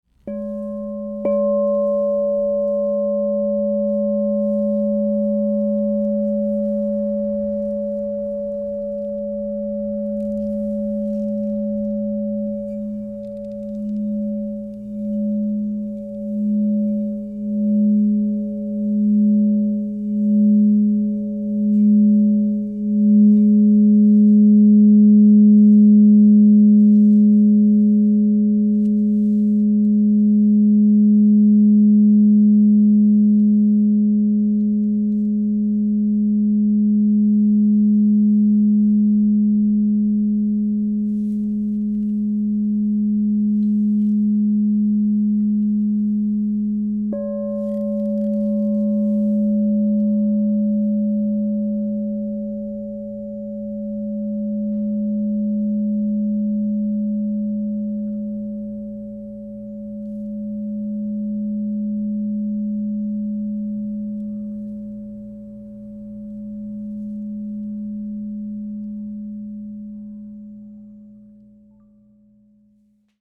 Crystal Tones® Dead Sea Salt 10 Inch G# Singing Bowl
The 10-inch size delivers rich, resonant tones that fill any space with harmonious frequencies, making this bowl ideal for meditation, sound therapy, and energy work.
Experience this 10″ Crystal Tones® alchemy singing bowl made with Dead Sea Salt, Smokey Quartz, Aqua Aura Gold (inside) in the key of G# +15.
G#
528Hz (+)